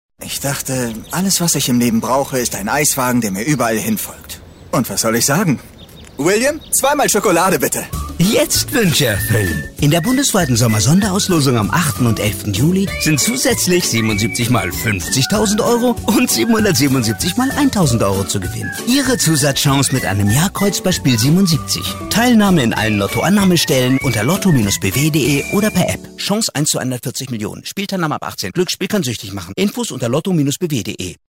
Werbung Radiospot